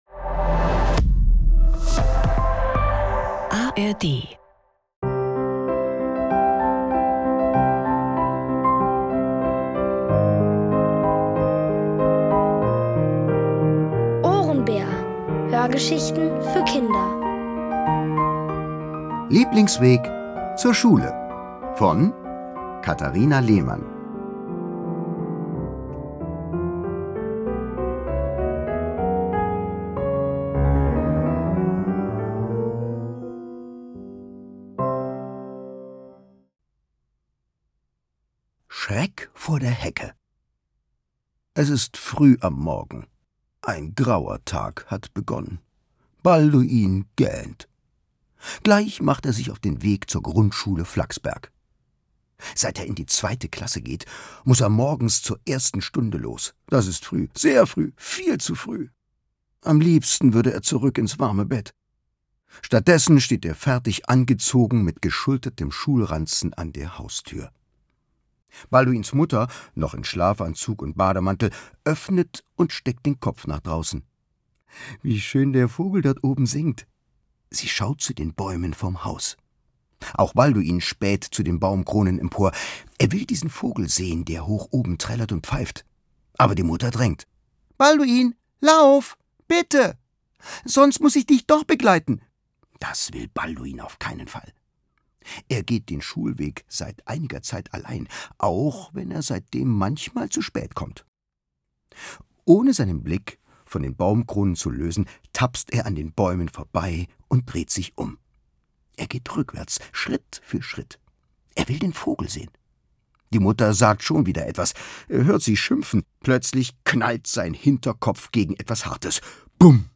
Es liest: Thomas Nicolai.